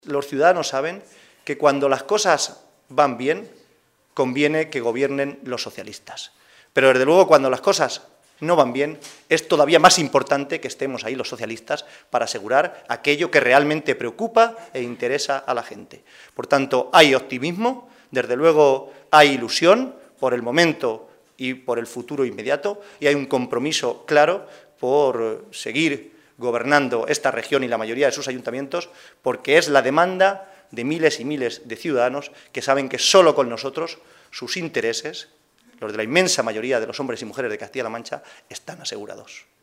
El secretario de Organización del PSOE de C-LM, José Manuel Caballero, ha asegurado hoy, durante la celebración del Comité Regional de este partido, que “los socialistas de C-LM nos hemos conjurado para no dar ni un paso atrás en el mantenimiento y mejora de lo que más importa a los ciudadanos de nuestra Región, que es una sanidad y una educación pública de calidad, y una política social dirigida a los que más lo necesitan”.